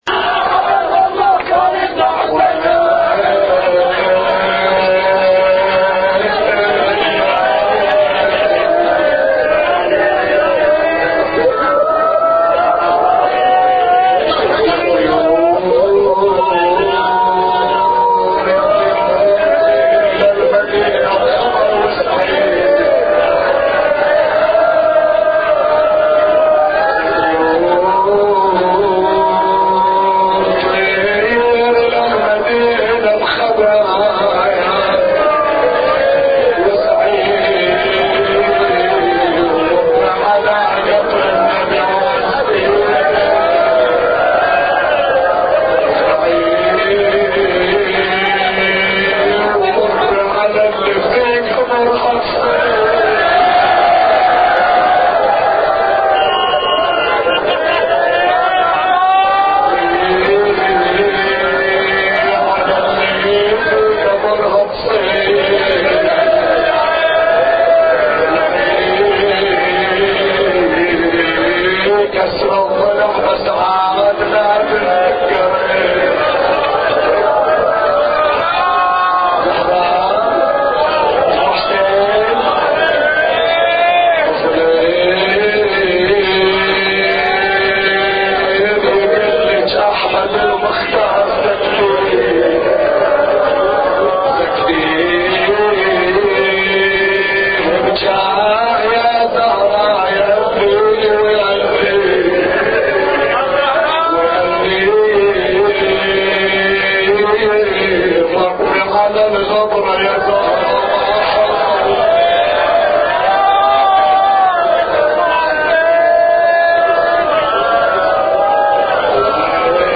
أبيات حسينية – ليلة العاشر من شهر محرم